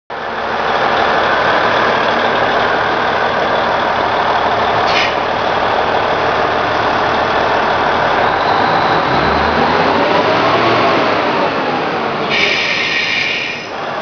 キヤ97アイドリング音
変な見た目に反し、正統派なディーゼルカーの音。